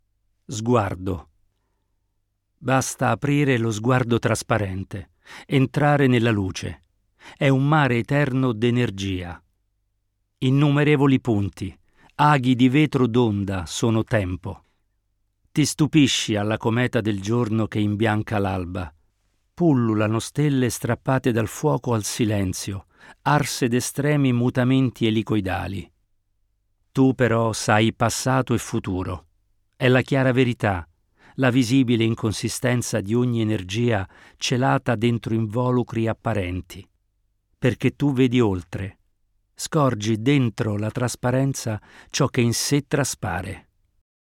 Nell’ audio, SGUARDO letta dall’ attore